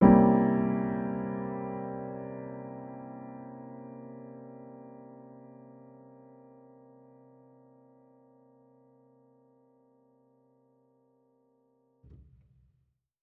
Index of /musicradar/jazz-keys-samples/Chord Hits/Acoustic Piano 2
JK_AcPiano2_Chord-C7b9.wav